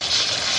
描述：视频juego en isla Pasos sobre agua（里约热内卢）